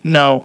synthetic-wakewords
ovos-tts-plugin-deepponies_Apple Bloom_en.wav